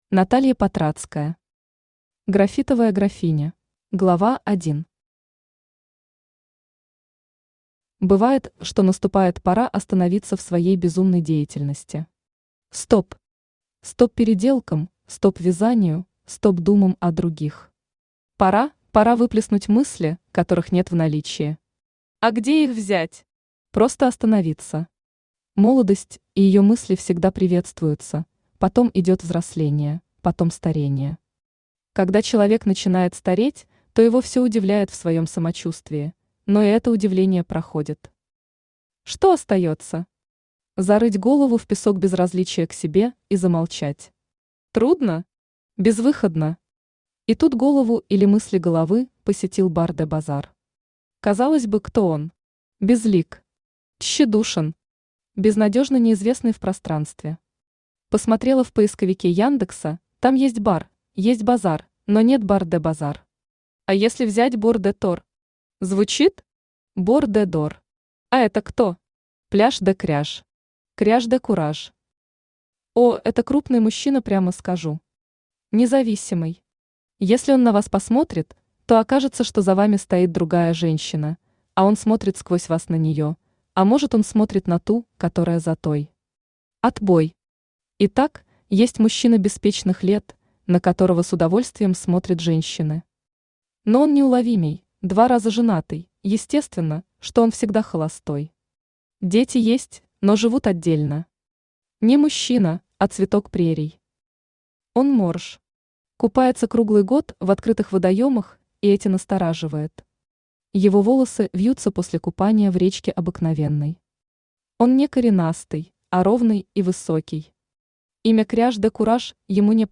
Аудиокнига Графитовая графиня | Библиотека аудиокниг
Aудиокнига Графитовая графиня Автор Наталья Владимировна Патрацкая Читает аудиокнигу Авточтец ЛитРес.